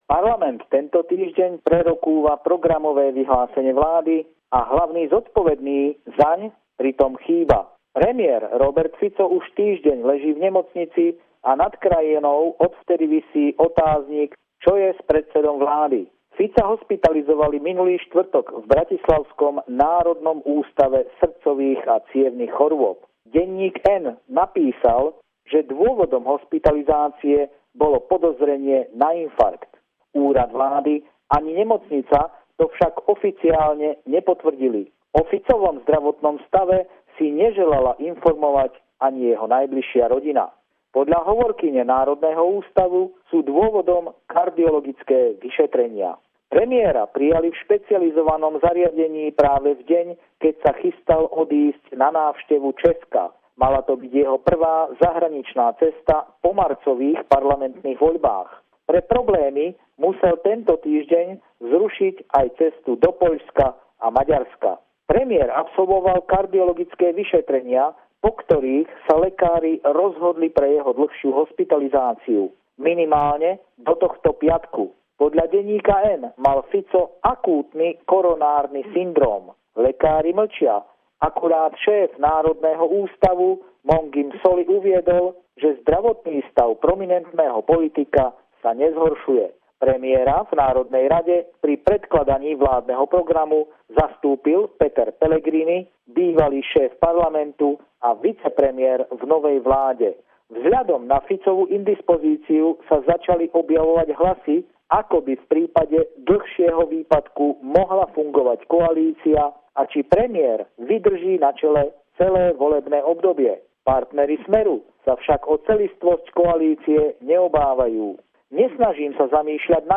Pravidelný telefonát týždňa kolegu z Bratislavy